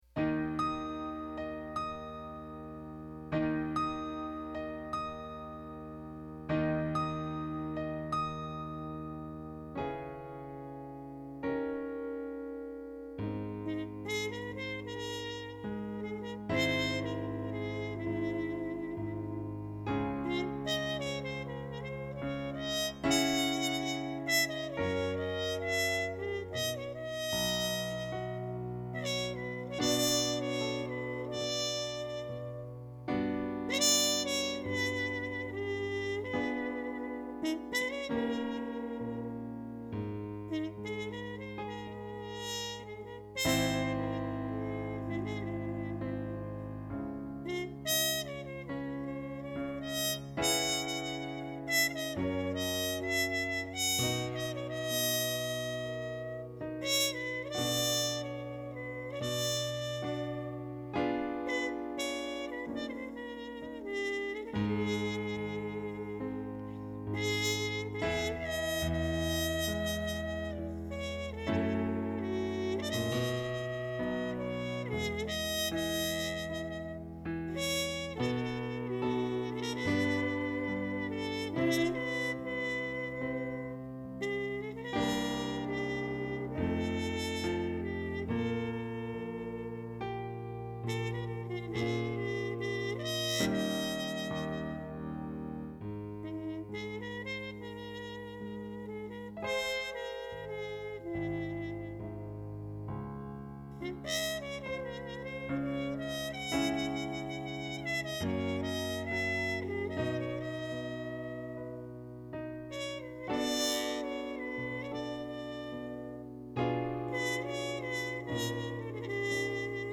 Here Comes Life (jazz ballad) - MP3